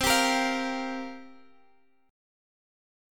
Listen to C7sus4 strummed